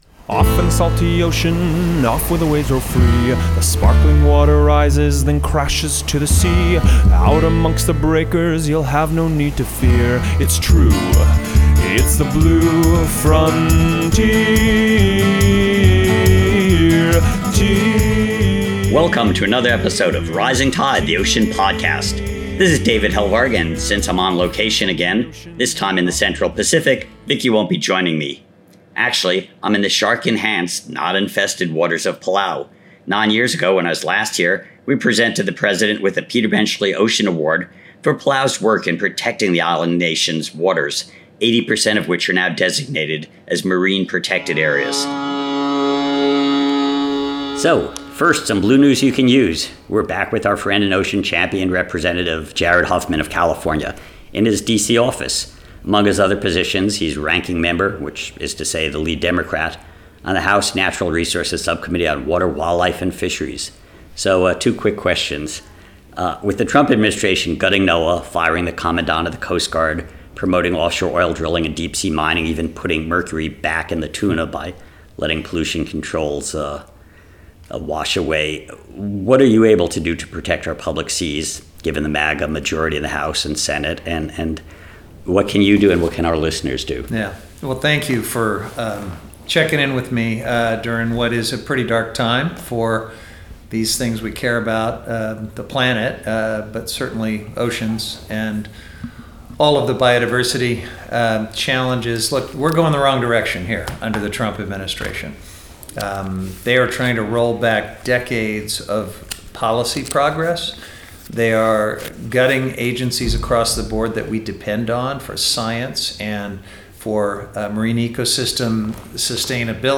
a short Blue News update with Congressman Jared Huffman in his DC office
interviews